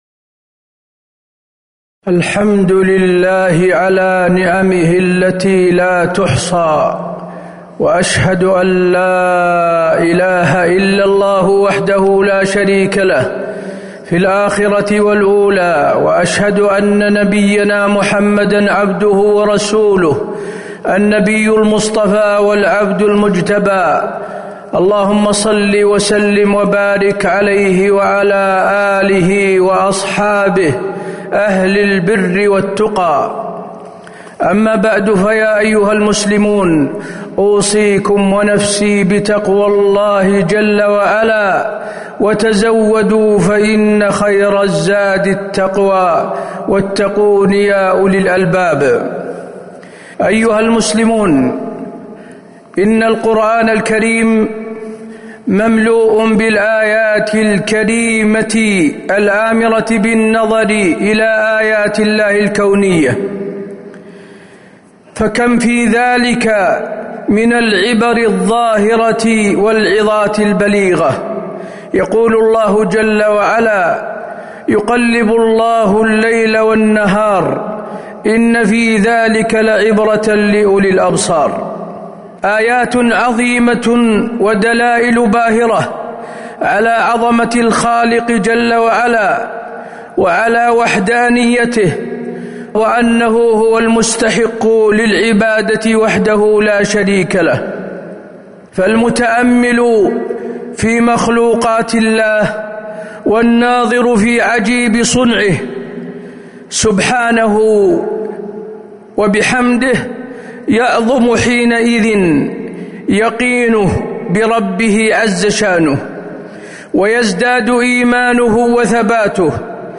تاريخ النشر ٢٦ شوال ١٤٤٣ هـ المكان: المسجد النبوي الشيخ: فضيلة الشيخ د. حسين بن عبدالعزيز آل الشيخ فضيلة الشيخ د. حسين بن عبدالعزيز آل الشيخ النظر في آيات الله الكونية